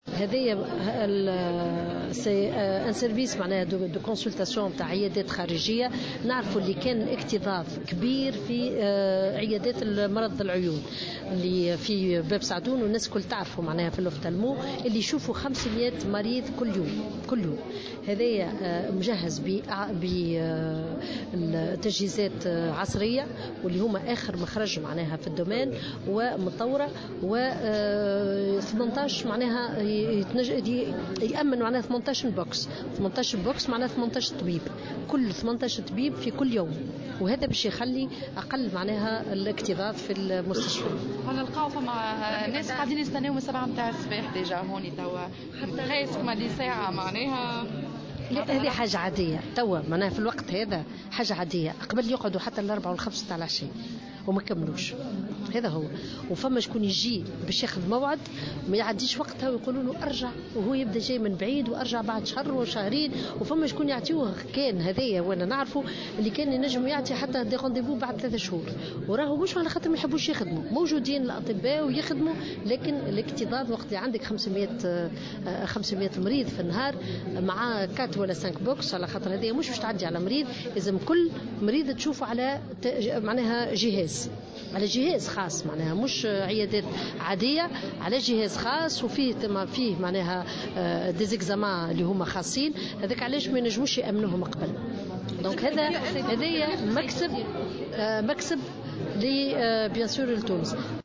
La ministre de la Santé, Samira Merai a inauguré aujourd'hui 18 nouvelles salles de soins à l'hôpital Hedi Raies d'ophtalmologie de Tunis.